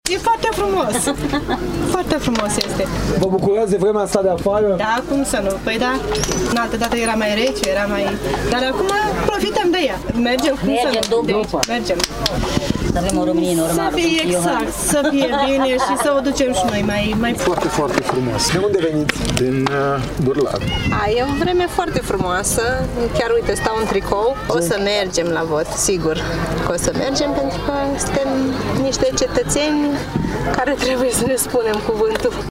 Mulţi turişti au ales să încingă un grătar, înainte de a ajunge la secţia de votare:
vox-gratar.mp3